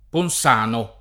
ponS#no] top. (Tosc.) — una frazione di Volterra, una di Empoli — doppie grafie, Ponsano e Ponzano, attestate per altri luoghi di Toscana — ma con -z- una frazione di Prato e una di Pistoia, e così pure le non poche località omonime delle altre regioni